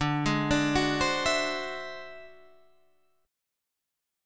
D 9th Flat 5th